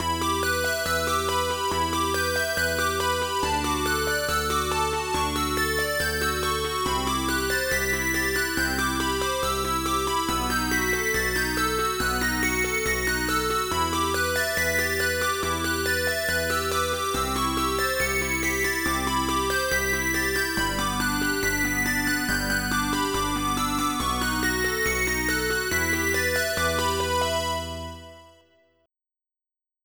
Result: Lead melody transposed up by a perfect fifth (+7 semitones)
8-bit-game-soundtrack_fith.wav